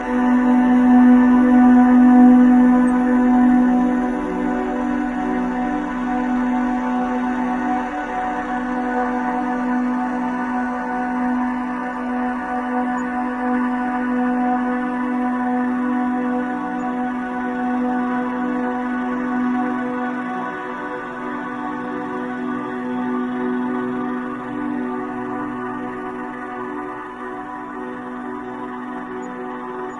描述：女孩的声音在唱歌。 麦克风：C3 BEHRINGEREDIROL UA4FX软件：CUBASE 5
标签： 唱歌 声音 女孩 人类 合唱团 声乐 女性 女人
声道立体声